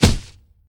refereePig_impact_01.ogg